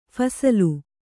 ♪ phasalu